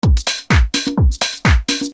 bicie_loop_dance.mp3